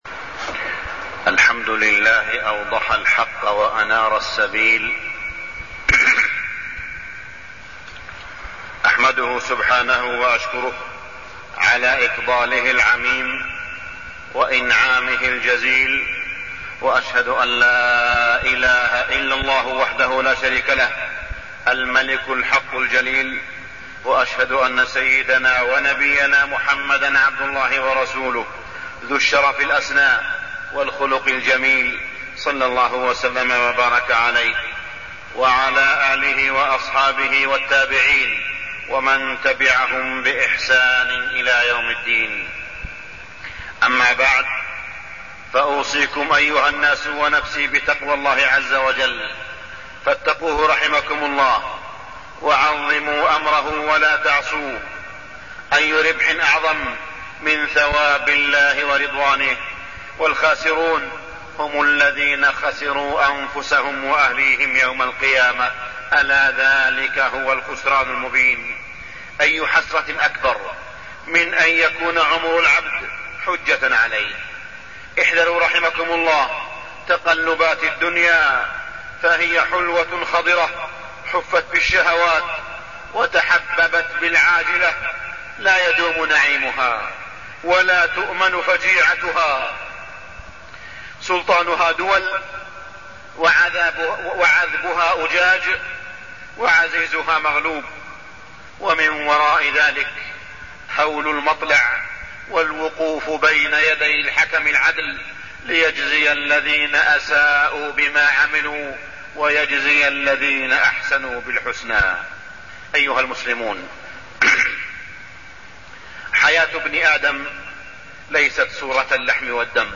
تاريخ النشر ٩ جمادى الأولى ١٤٢٠ هـ المكان: المسجد الحرام الشيخ: معالي الشيخ أ.د. صالح بن عبدالله بن حميد معالي الشيخ أ.د. صالح بن عبدالله بن حميد الإيمان بالغيب